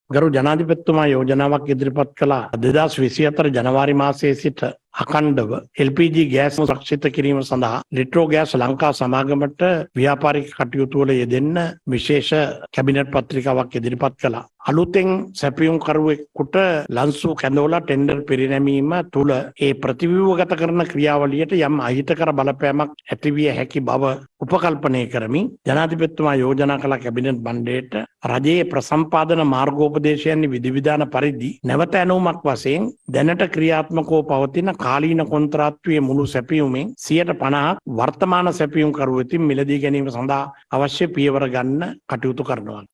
මේ වන විට 2024 – 2025 කාලසීමාව සදහා එල්.පී. ගෑස් සැපයීම සදහා ලංසු කැඳවා ඇති බවයි කැබිනට් ප්‍රකාශක අමාත්‍ය බන්දුල ගුණවර්ධන මහතා පැවසුවේ.
අද පැවති කැබිනට් තීරණ දැනුම් දීමේ මාධ්‍ය හමුවේදී ඔහු මේ බව සඳහන් කළා.